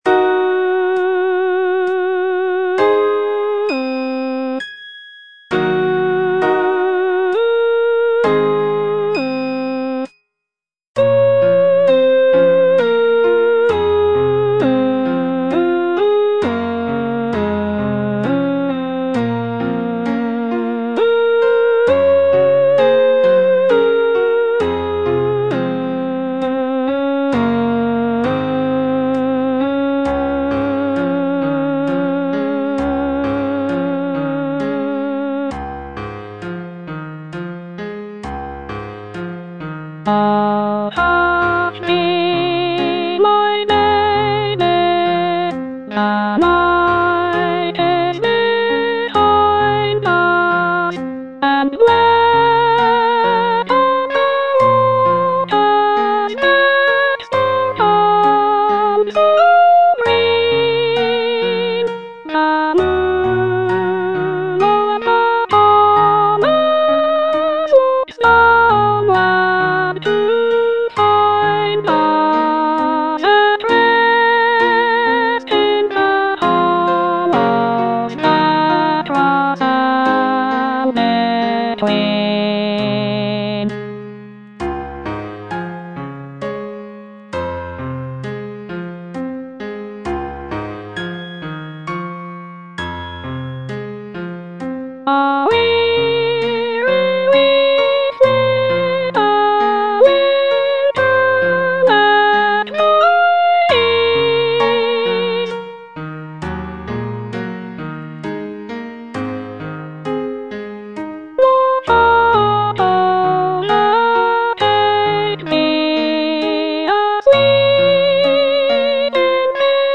Soprano (Voice with metronome